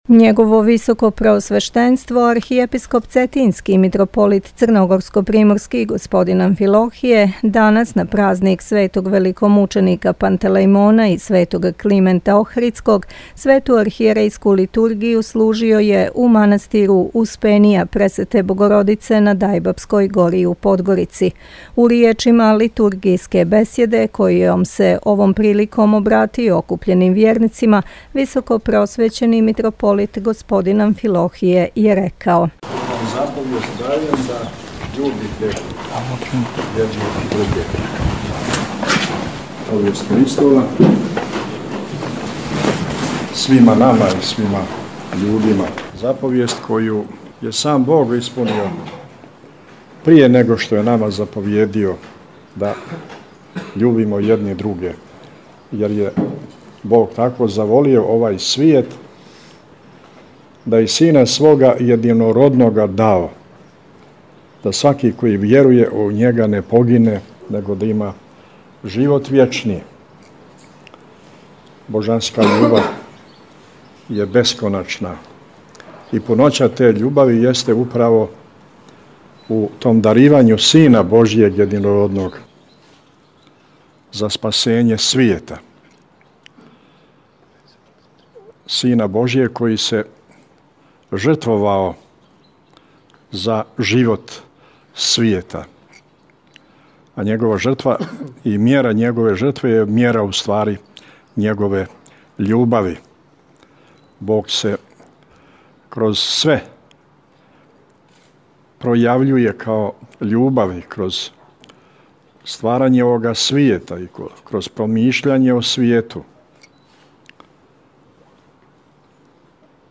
Tagged: Бесједе